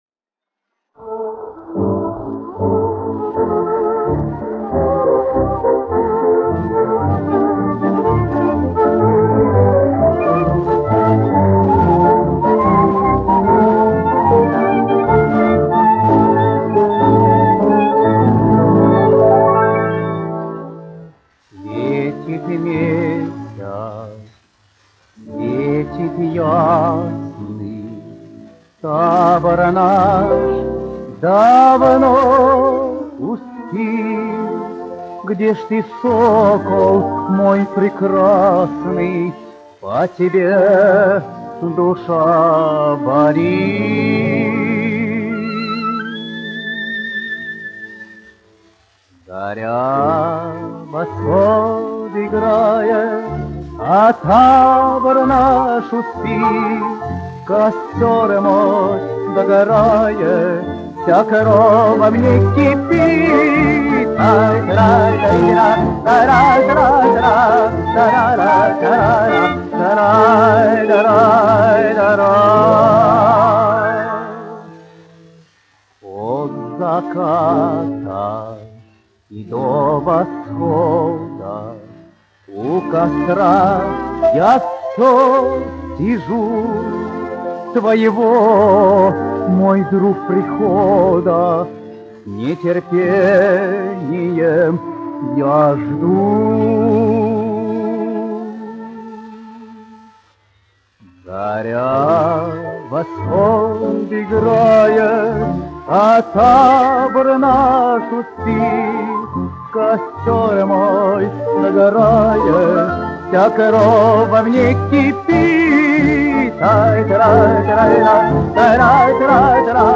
1 skpl. : analogs, 78 apgr/min, mono ; 25 cm
Romances (mūzika)
Latvijas vēsturiskie šellaka skaņuplašu ieraksti (Kolekcija)